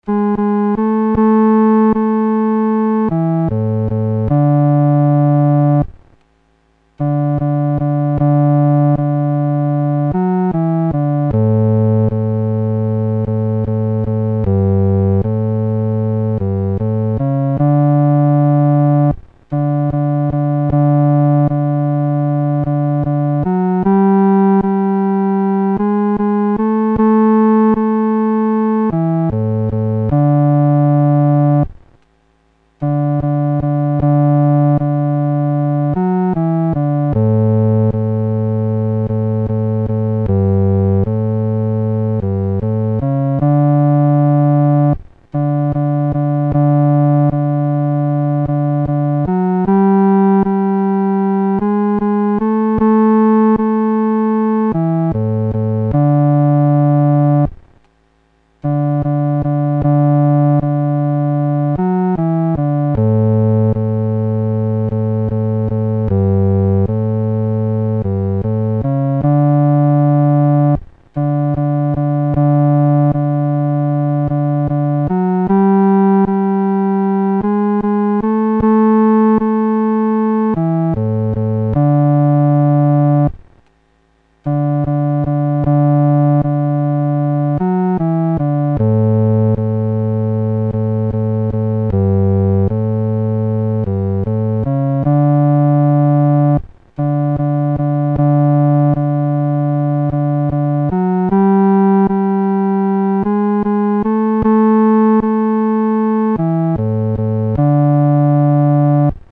独奏（第四声）